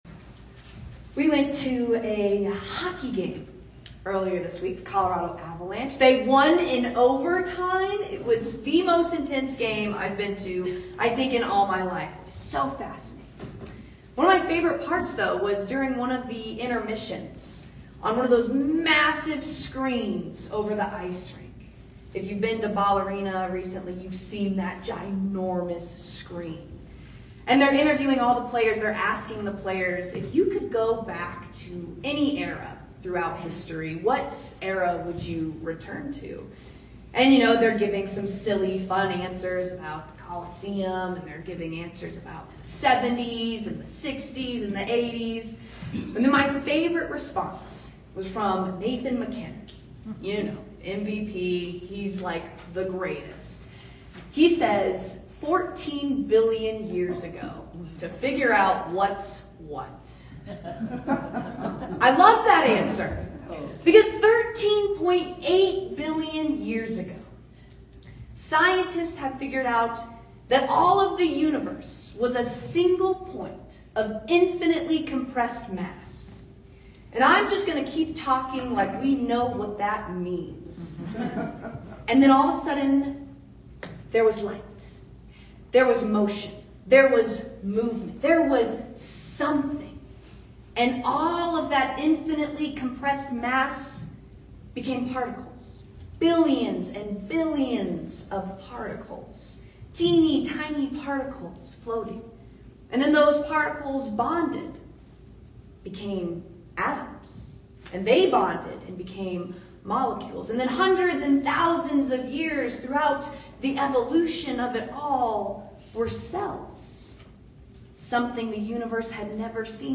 Sermons | Covenant Presbyterian DTC